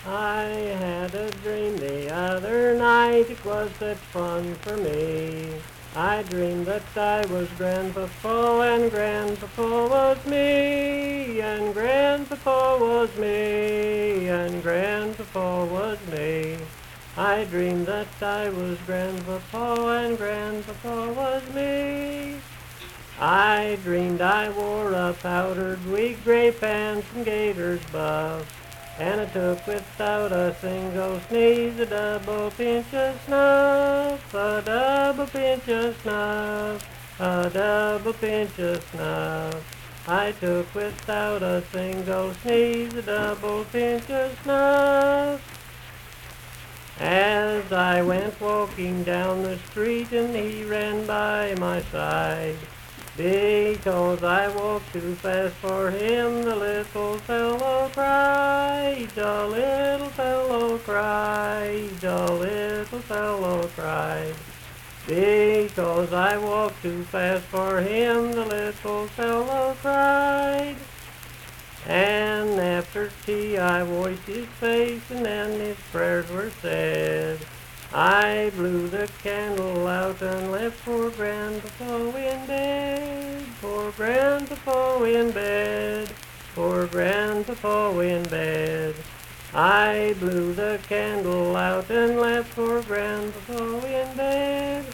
Unaccompanied vocal music performance
Verse-refrain 4d(4w/R).
Voice (sung)